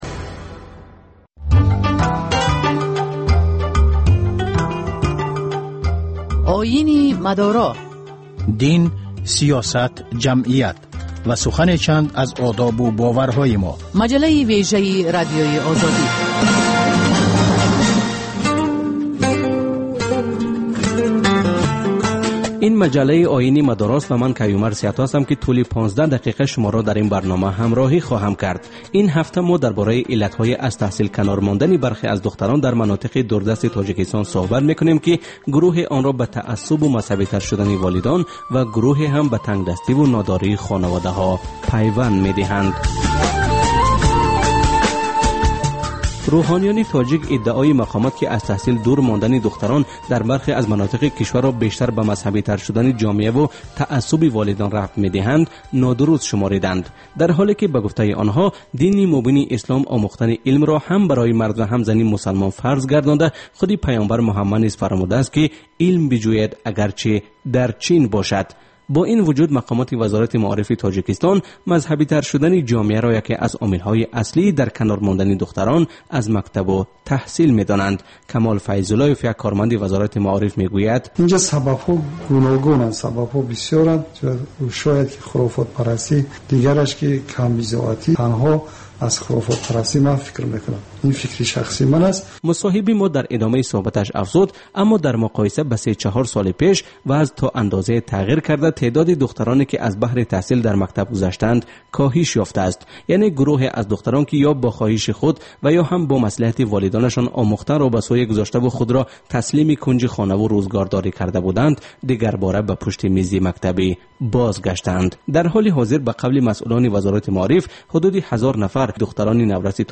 Дин ва ҷомеа. Гузориш, мусоҳиба, сӯҳбатҳои мизи гирд дар бораи муносибати давлат ва дин. Шарҳи фатво ва нукоти мазҳабӣ.